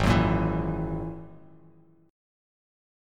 G#7sus2#5 chord